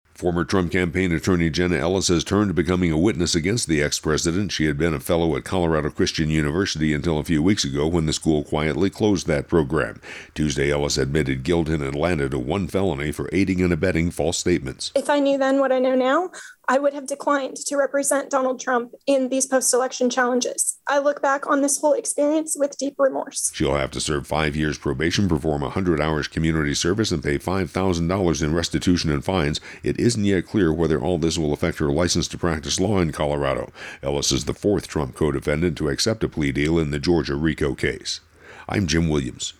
(Ellis Accepts Plea Deal wrap                        :40)